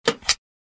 دانلود آهنگ موس 18 از افکت صوتی اشیاء
جلوه های صوتی
برچسب: دانلود آهنگ های افکت صوتی اشیاء دانلود آلبوم صدای کلیک موس از افکت صوتی اشیاء